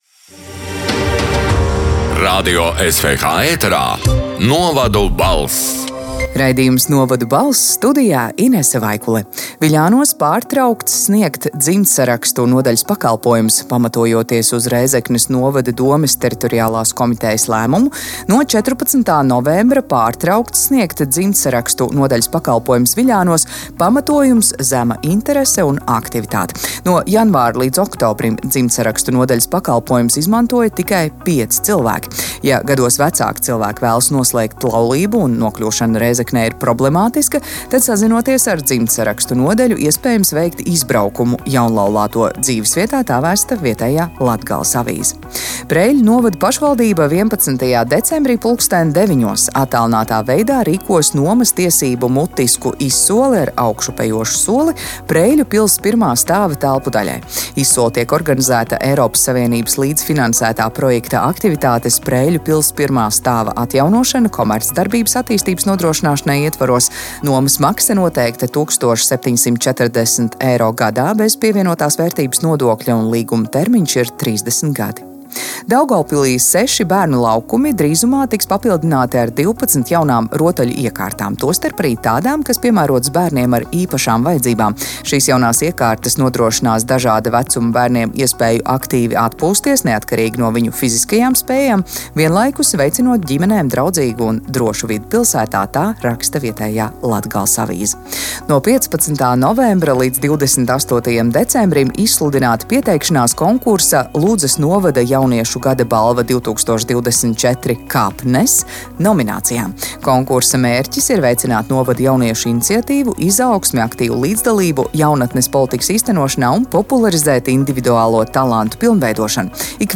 “Novadu balss” 22. novembra ziņu raidījuma ieraksts: